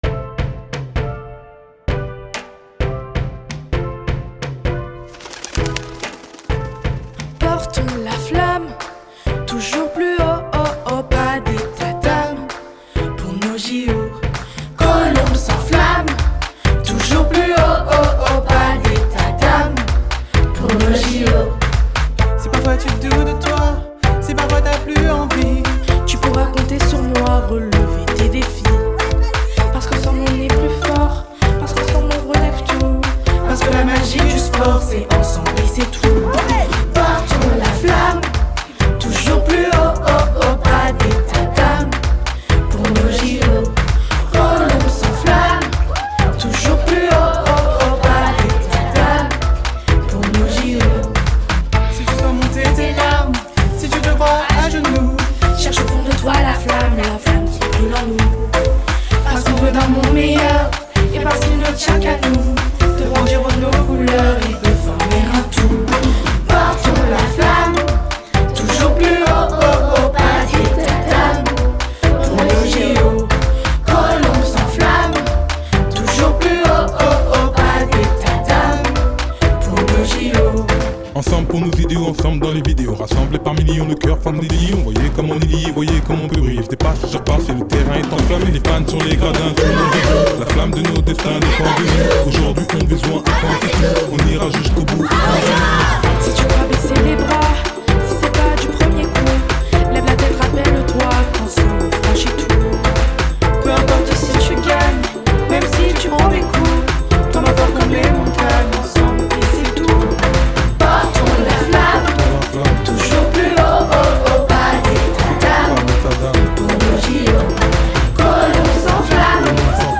Matinée de la flamme olympique en maternelle – 4 juin 2024 –
8_CHANT-final_Porter-la-flamme.mp3